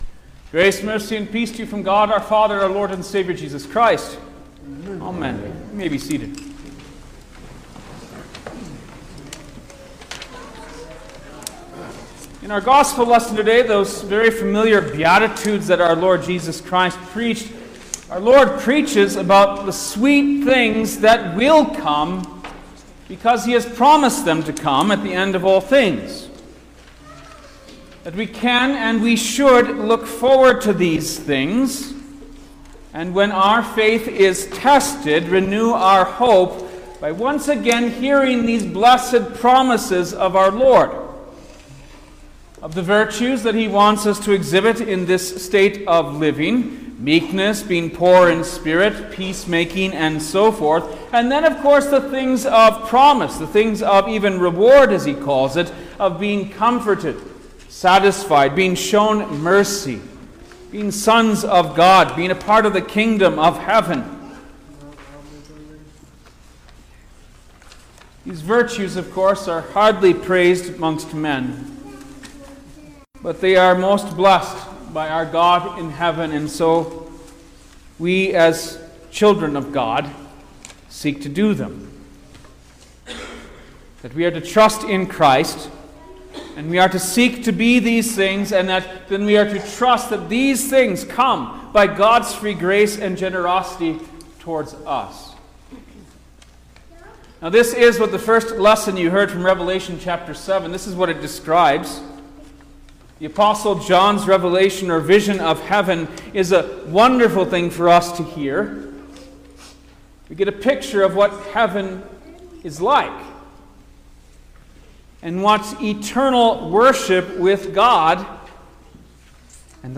November-3_2024_All-Saints-Day_Sermon-Stereo.mp3